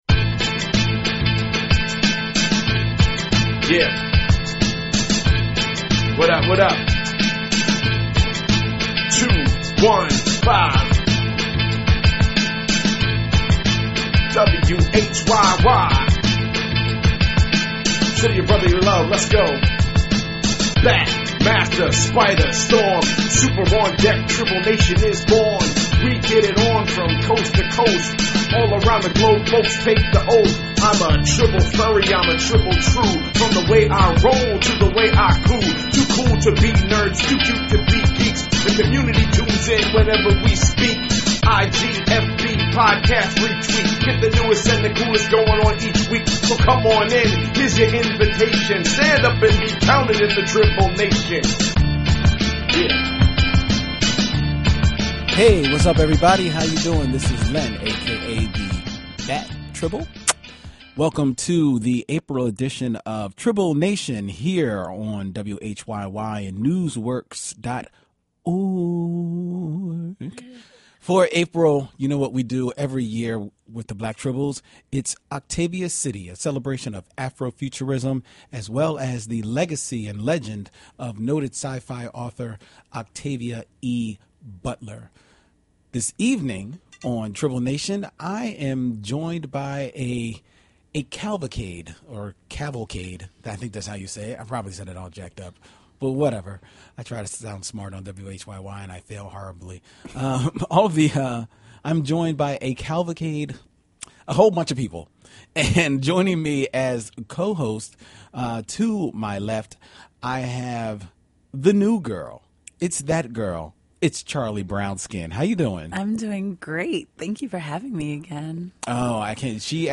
So this was the second time in my life (and the second time this month!) I’ve used a real-life radio mic in a real-life radio studio.